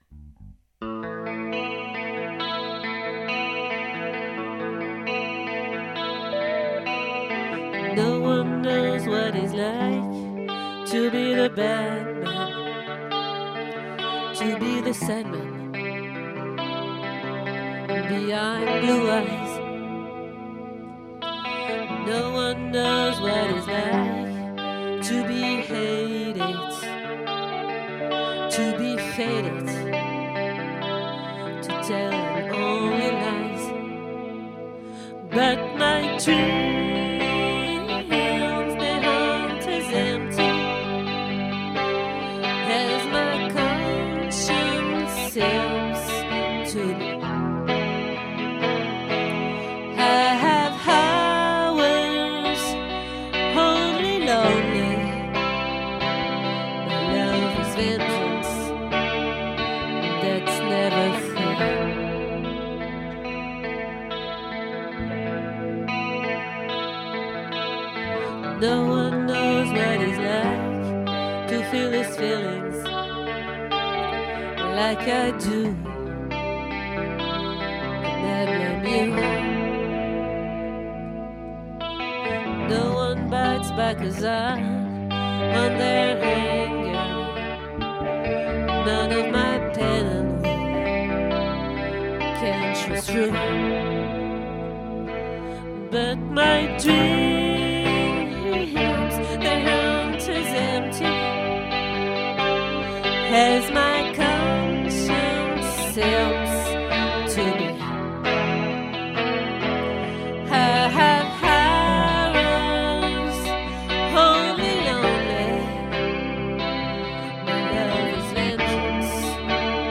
🏠 Accueil Repetitions Records_2024_03_13